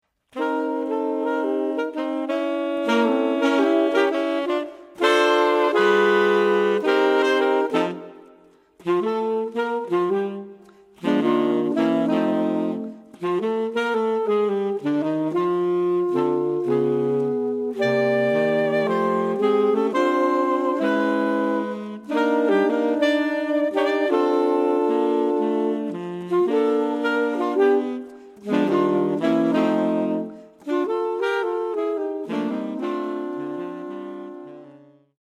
Oeuvre pour quatuor de saxophones.